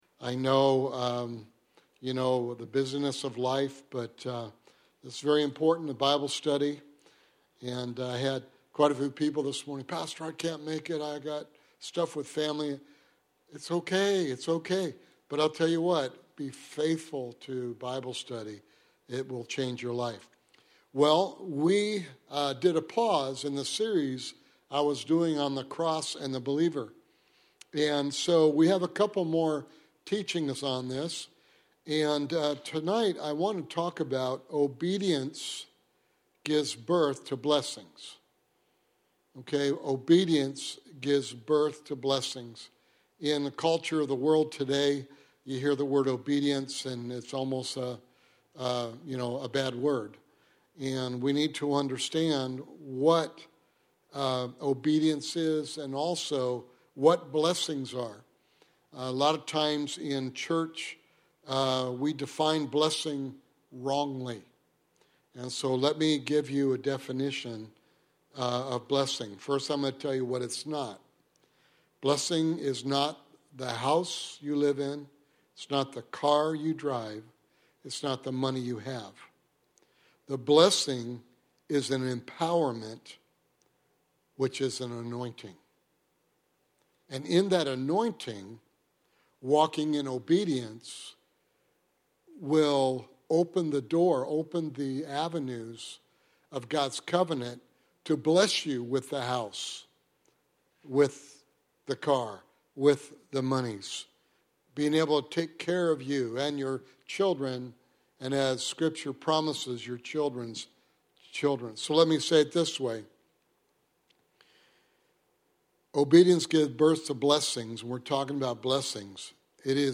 Sermon Series: The Cross & The Believer